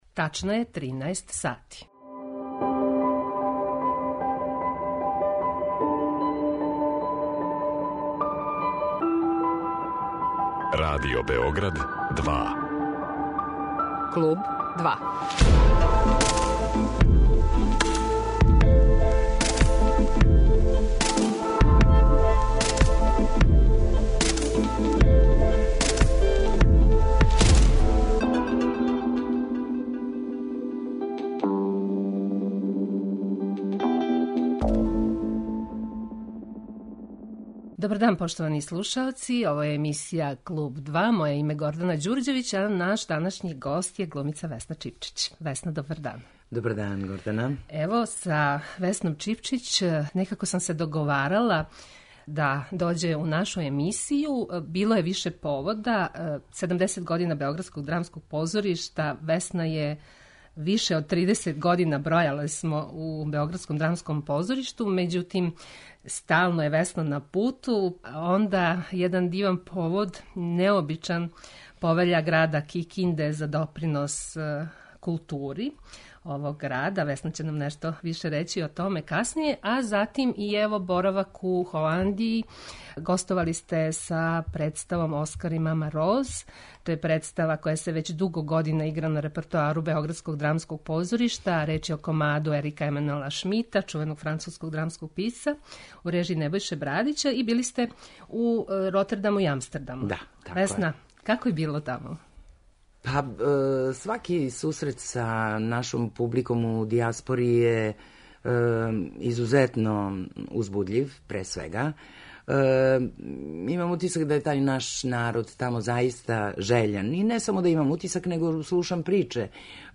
Наша позната глумица Весна Чипчић гошћа је емисије 'Клуб 2'.